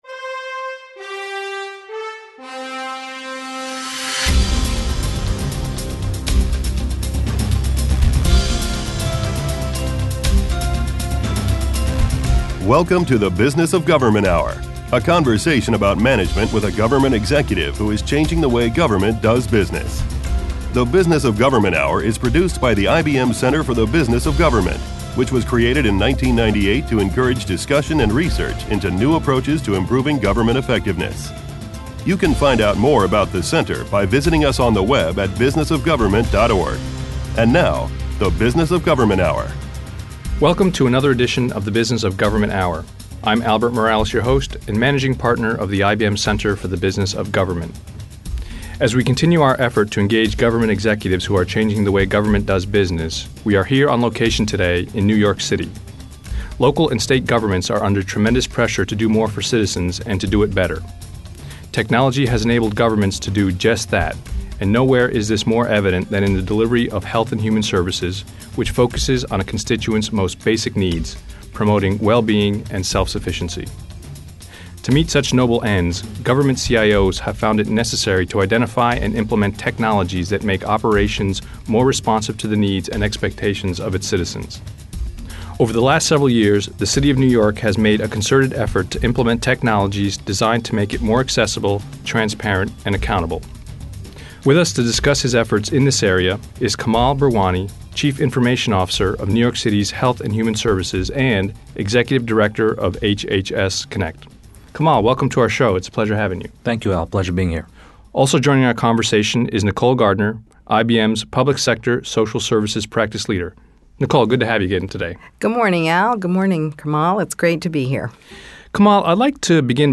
Radio Hour